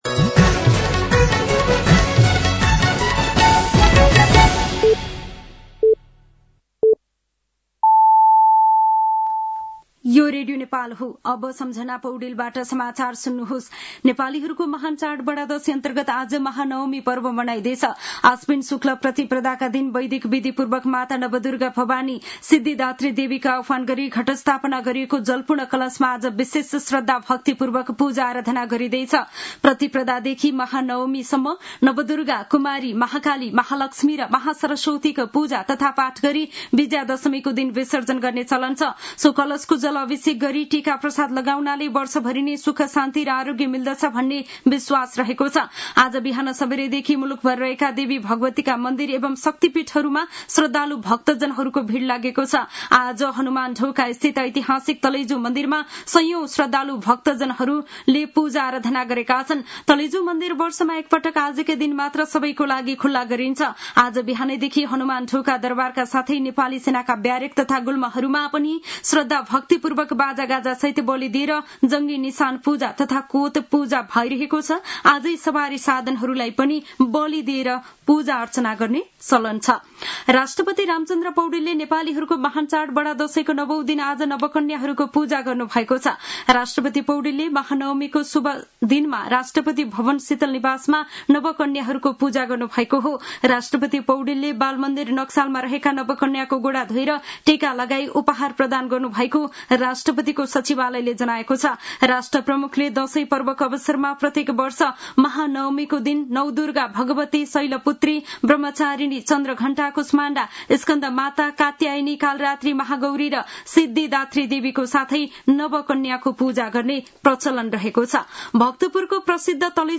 साँझ ५ बजेको नेपाली समाचार : १५ असोज , २०८२
5-pm-news-6-15.mp3